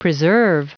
Prononciation du mot preserve en anglais (fichier audio)
Prononciation du mot : preserve